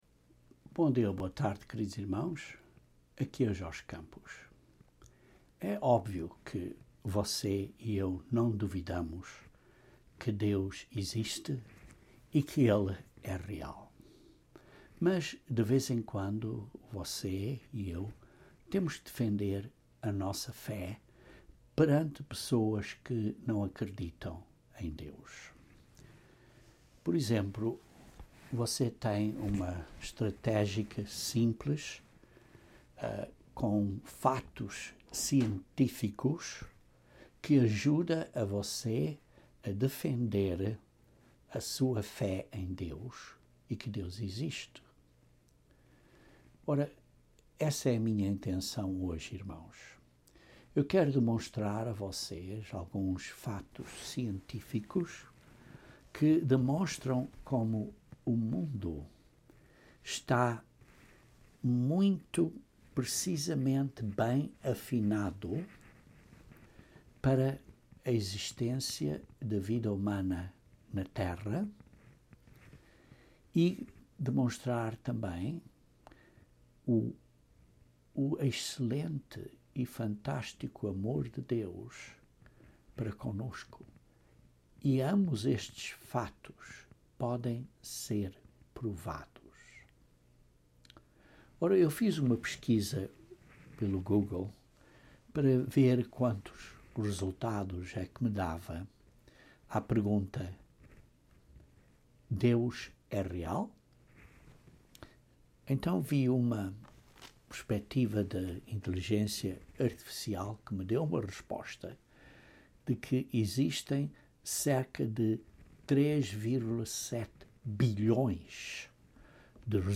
Este sermão aborda duas provas que Deus existe, baseadas na Verdade. A criação perfeitamente afinada é uma prova inegável.